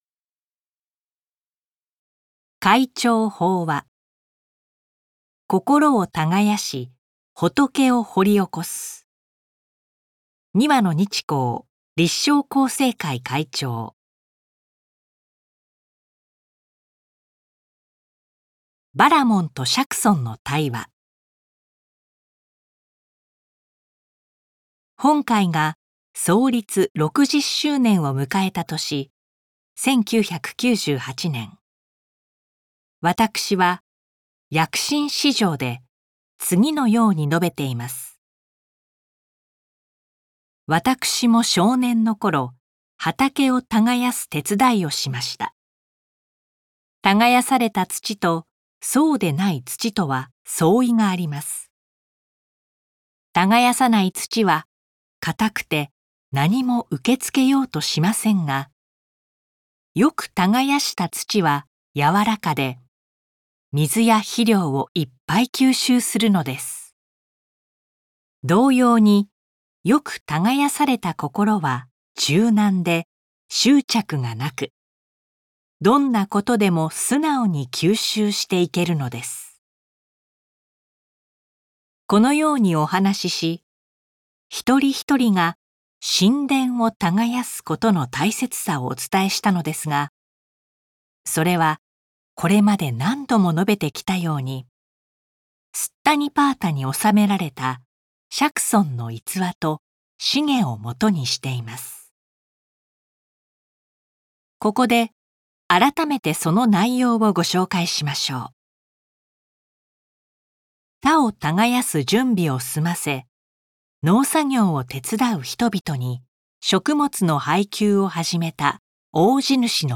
朗読MP3 podcast